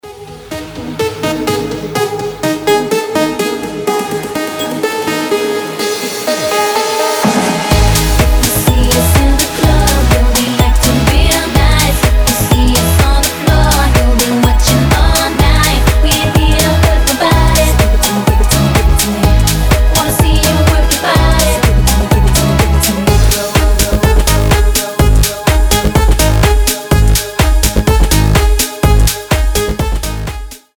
• Качество: 320, Stereo
Tech House
house
Прикольный клубняк на звонок